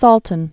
(sôltən)